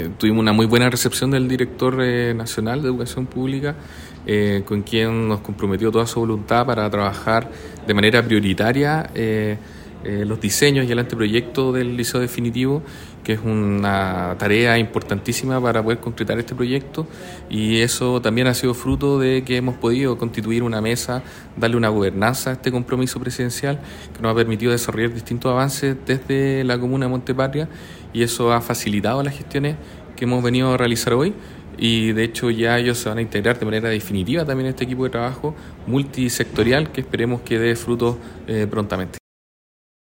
Al respecto el Delegado Presidencial Provincial, Galo Luna, indicó que
CUNA-LICEO-EDUARDO-FREI-MONTALVA_2-Galo-Luna-Penna-Delegado-Presidencial-Provincial.mp3